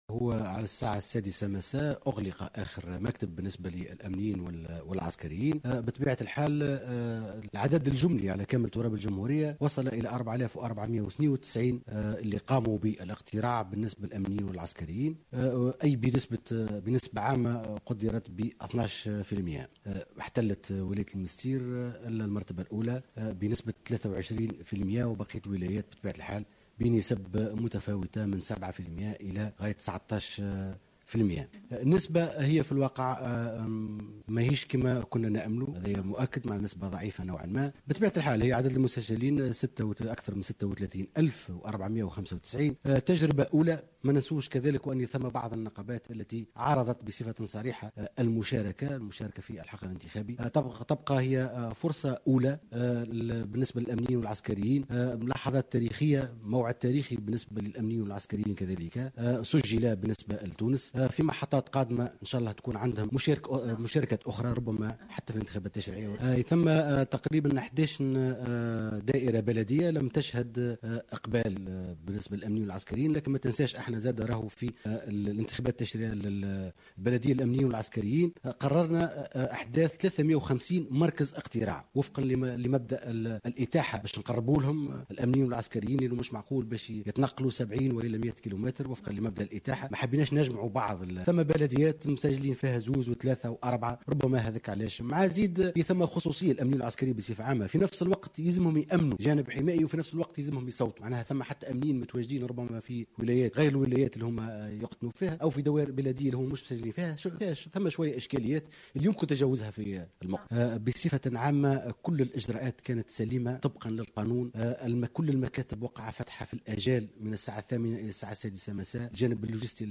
أكد محمد التليلي المنصري رئيس للهيئة العليا المستقلة للانتخابات في تصريح للقناة الوطنية الاولى أن العدد الجملي للأمنيين والعسكريين الذي قاموا اليوم بالإدلاء بأصواتهم في الانتخابات البلدية بلغ 4492 ناخبا بنسبة عامة قدرت ب12 بالمائة.